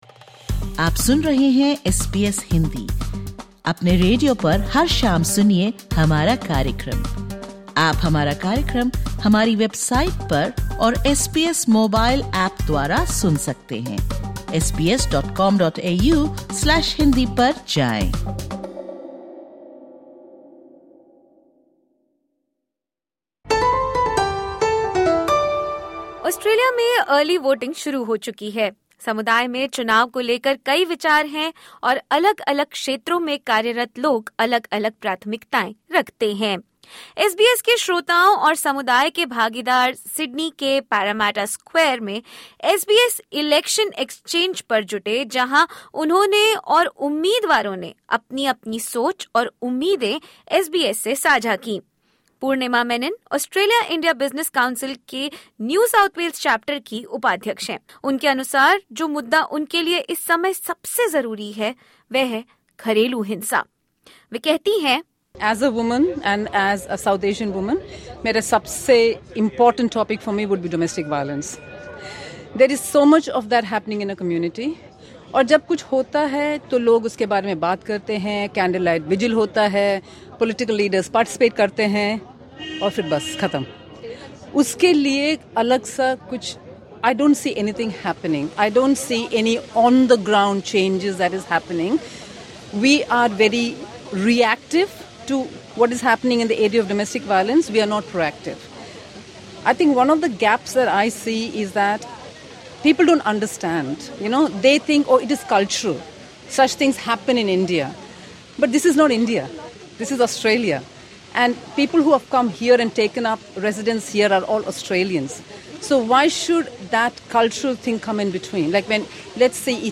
सिडनी के प्रतिष्ठित पैरामैटा स्क्वायर में एसबीएस ने 'इलेक्शन एक्सचेंज' का आयोजन किया, जहां समुदाय की आवाज़ें राजनीतिक नेताओं से सीधे जुड़ीं।